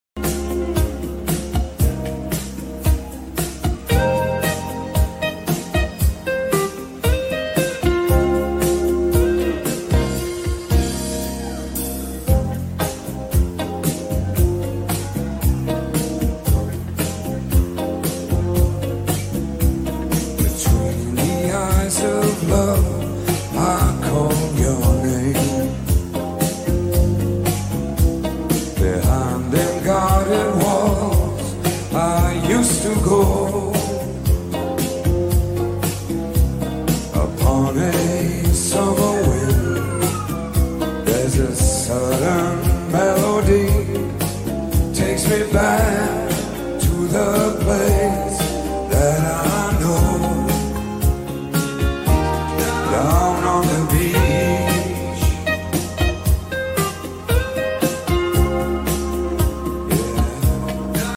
Live! Montreux
80smusic